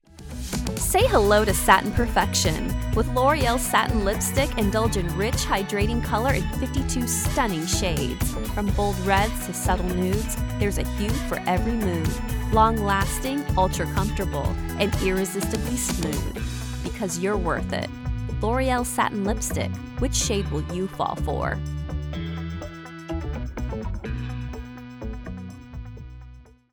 Voice-over artist with a warm, articulate, and soothing voice that brings calm and clarity to every project
Loreal Ad